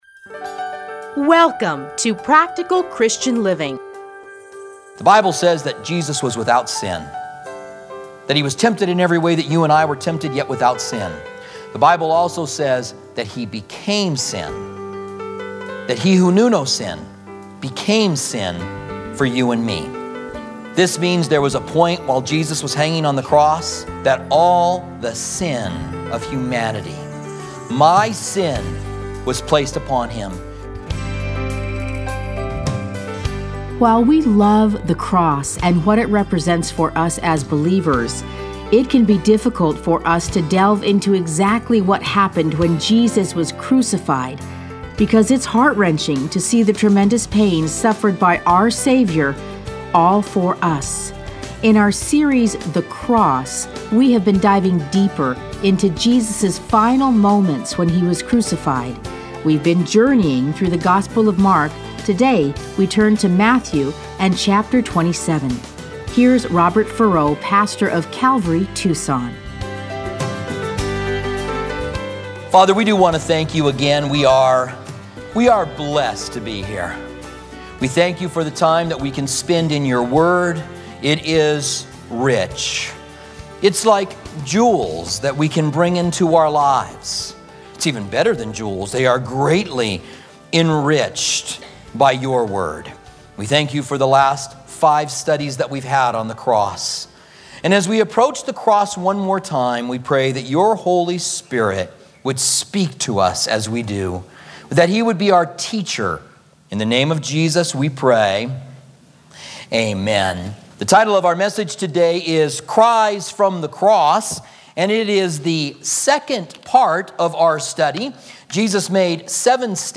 Listen to a teaching from Matthew 27:46-49, John 19:28 & 30 Luke 23:46.